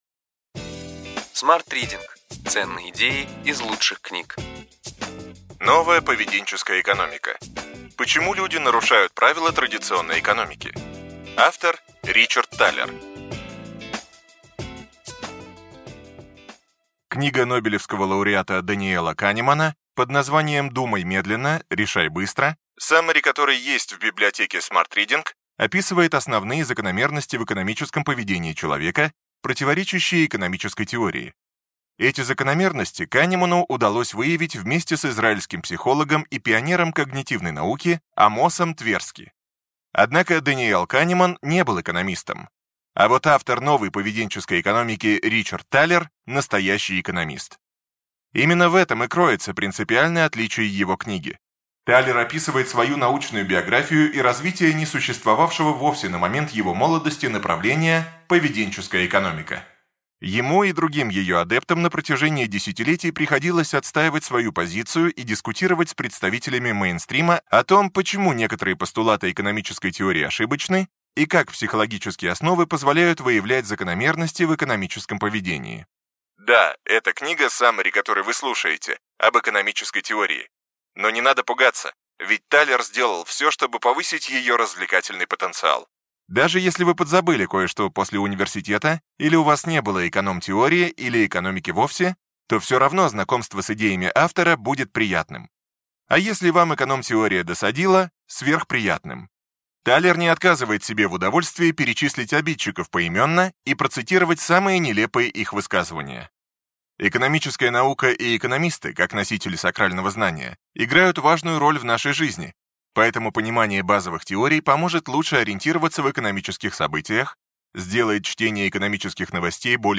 Аудиокнига Ключевые идеи книги: Новая поведенческая экономика. Почему люди нарушают правила традиционной экономики.